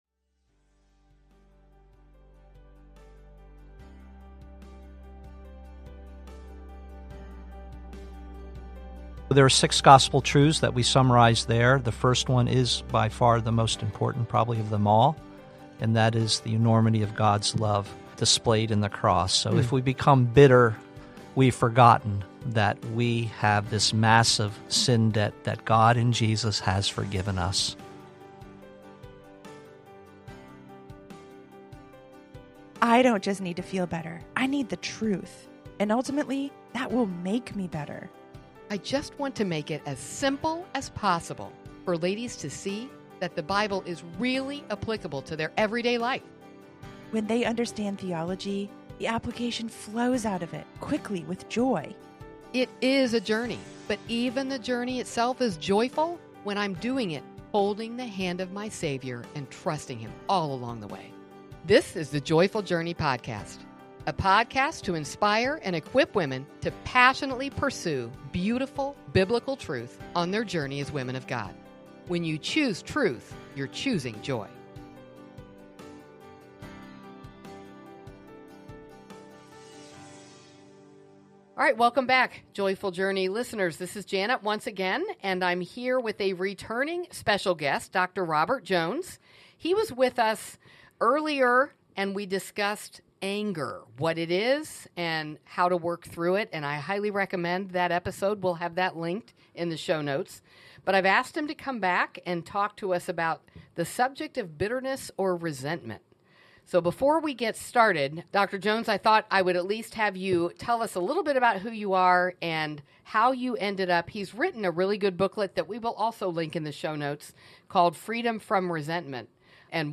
They explore how clinging to bitterness means assuming the role of judge, a role that belongs to God alone. Rooted in Matthew 18 and James 4, this conversation calls listeners to fix their eyes on the Cross, where Christ’s forgiveness is the only firm foundation for a forgiving heart.